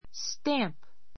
stǽmp